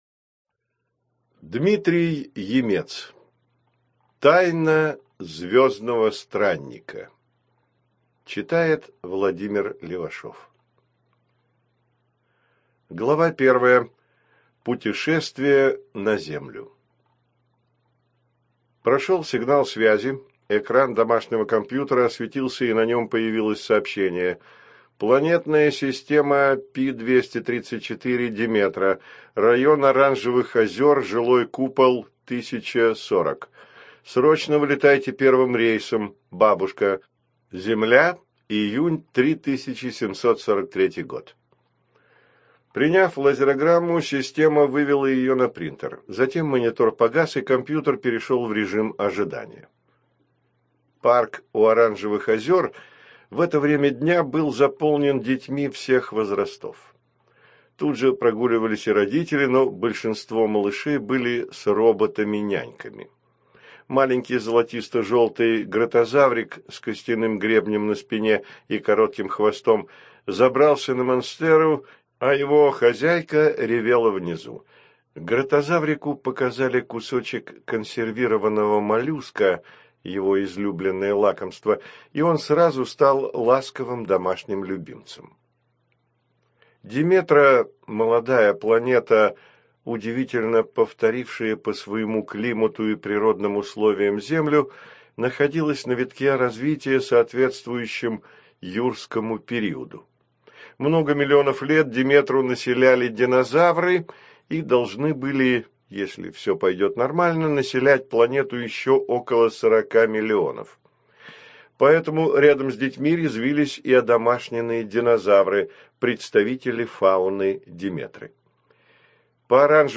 ЖанрФантастика, Детская литература